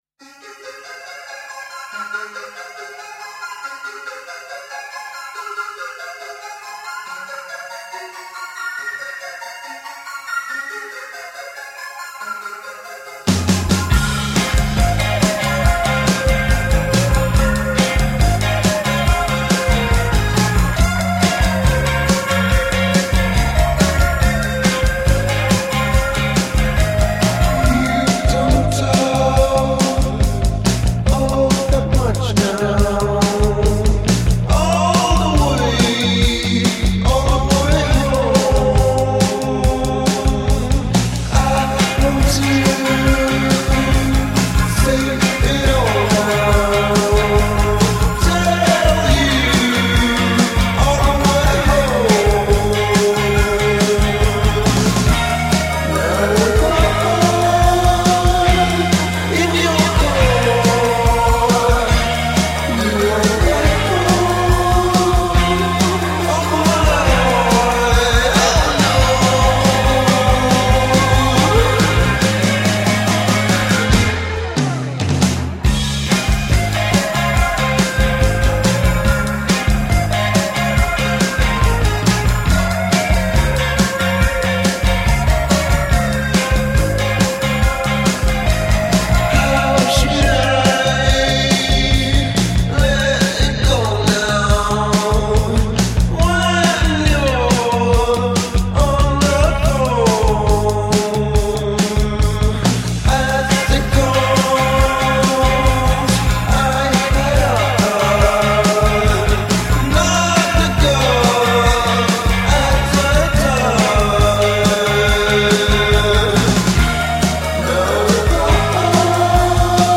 indie
instantly charming debut single